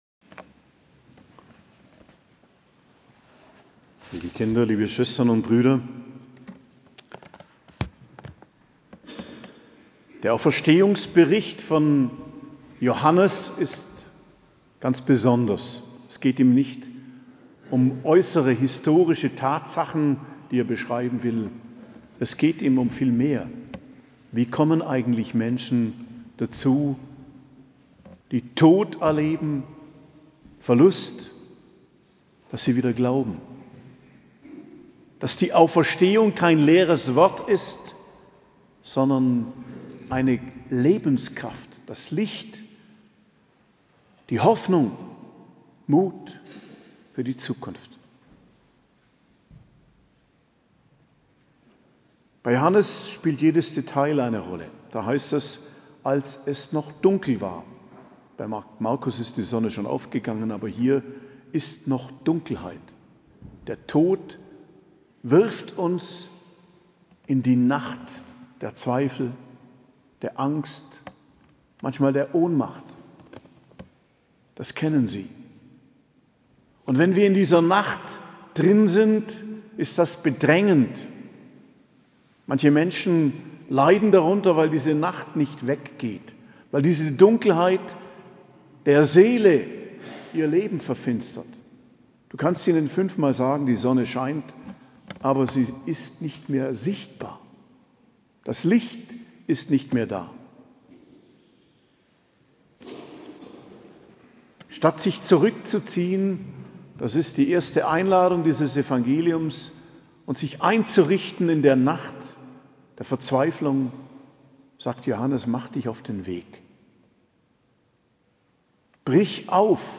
Predigt zum Ostersonntag, 31.03.2024 ~ Geistliches Zentrum Kloster Heiligkreuztal Podcast